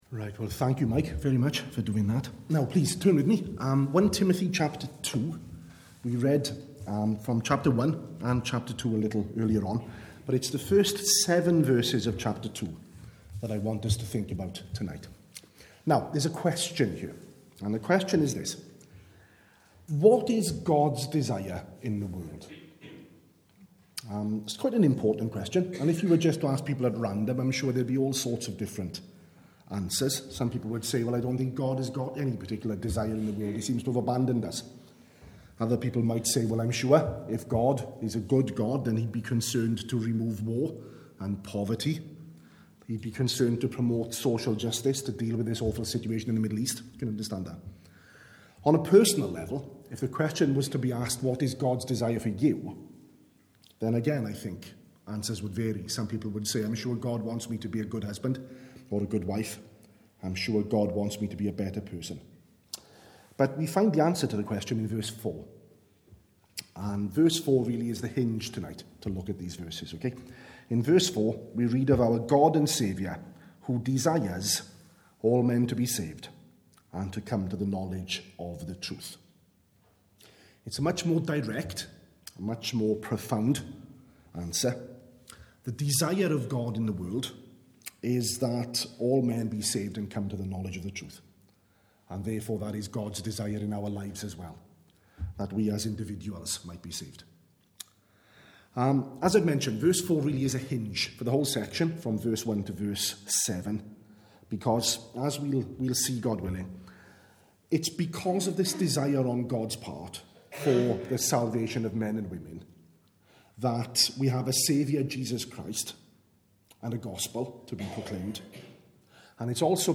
- 1 Timothy 2:1-7 By on 07 February 2016 at at the evening service.